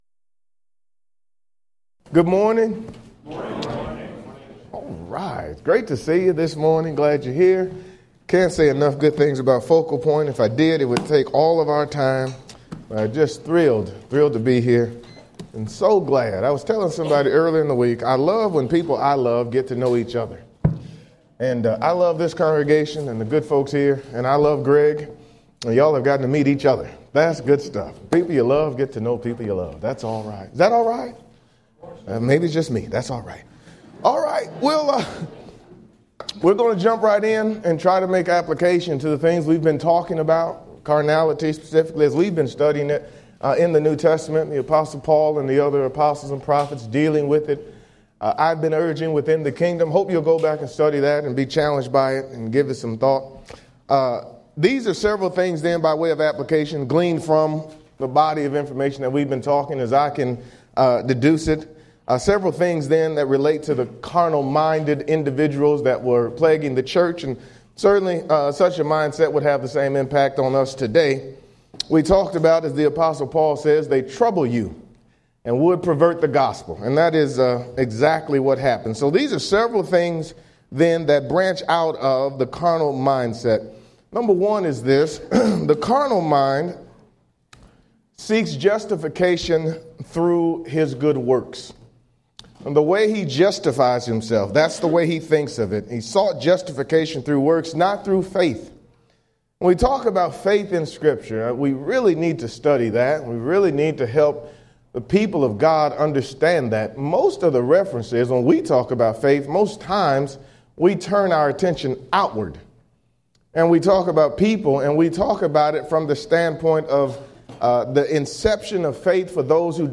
Title: Midday Keynote: Christian, Are You Carnal?
Event: 2014 Focal Point
lecture